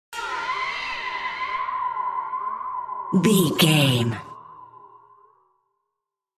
Sound Effects
Atonal
scary
ominous
haunting
eerie
spooky